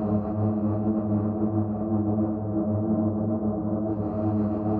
SS_CreepVoxLoopA-05.wav